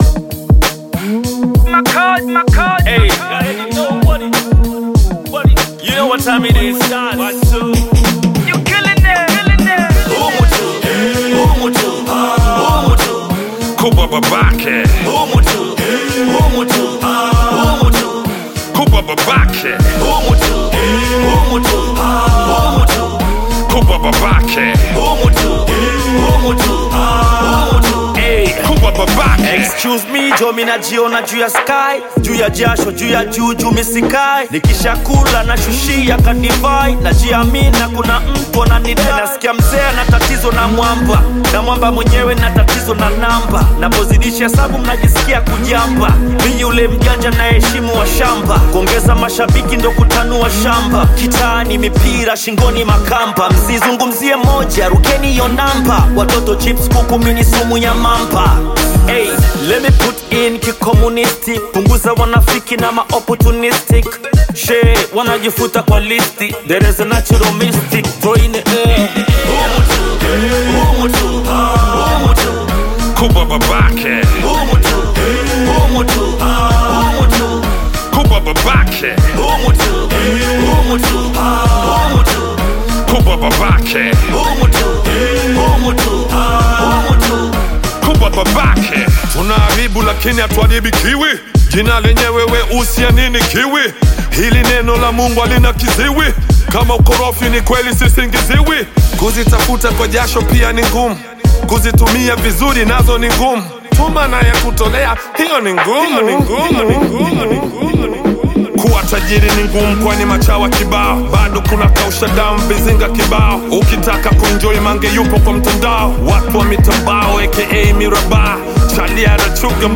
Bongo Flava
Tanzanian Hip-Hop group